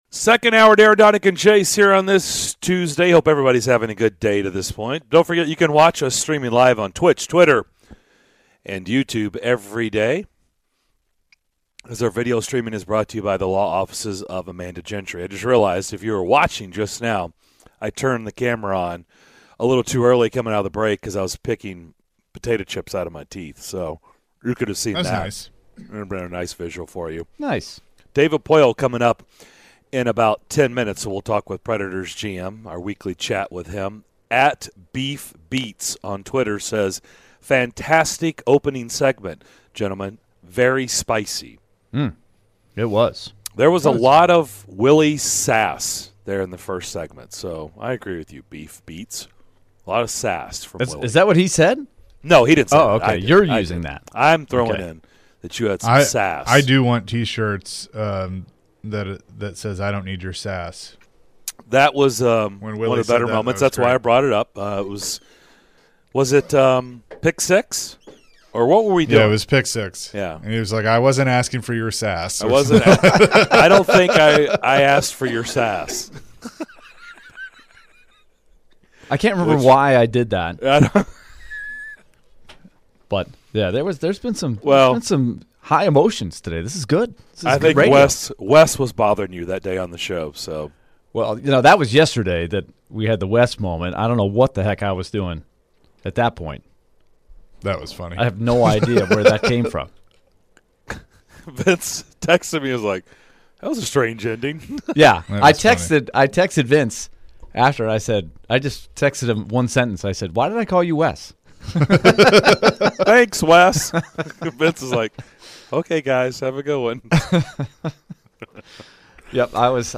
In the second hour of Tuesday's show: the guys chat with Predators GM David Poile, evaluate the Preds after the first 12 games of the season and more!